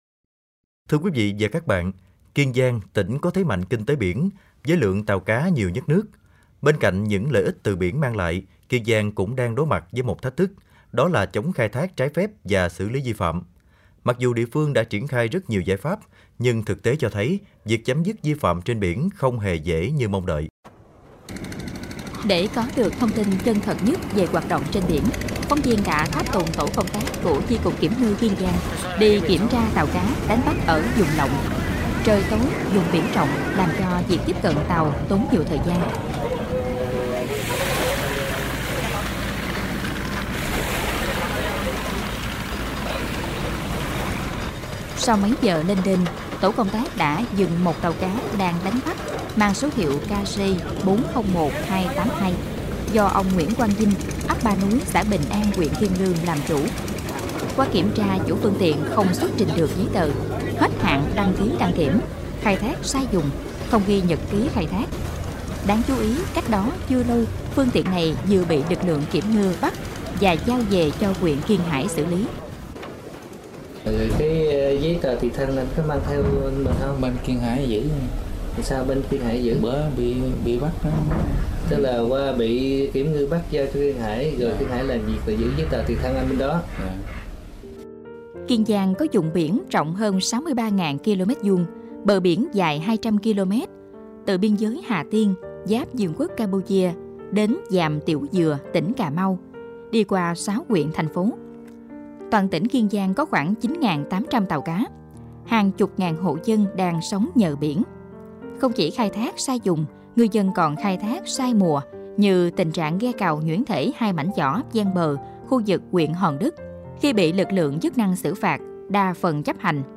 16295_KIENGIANG_ PHONGSU_ Kien_Giang_gian_nan_go_the_vang.mp3